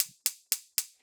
Gas Hob Ignition 01.wav